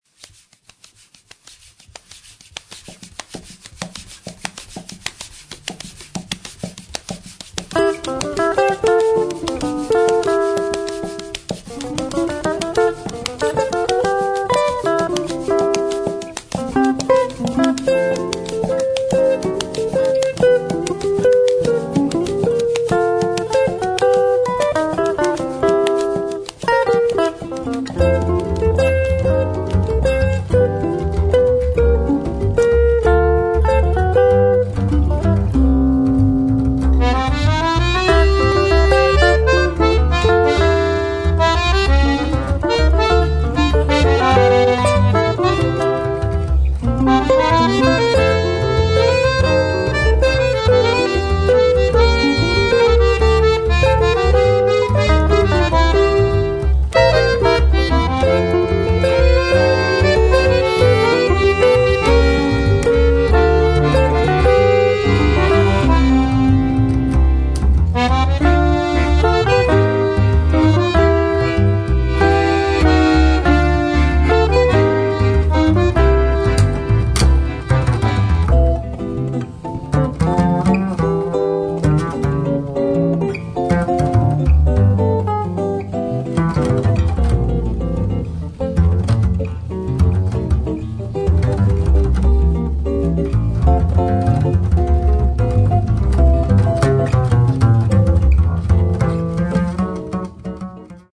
– Un quartet de jazz de plus !
– Yes, but there’s an accordion.
– And a double bass.
– And tender.
– No, it’s quite lively.